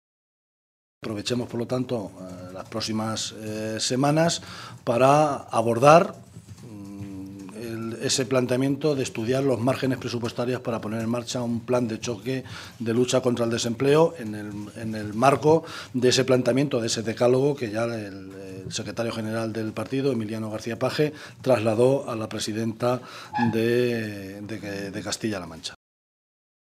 José Luís Martínez Guijarro, portavoz del Grupo Parlamentario Socialista
Cortes de audio de la rueda de prensa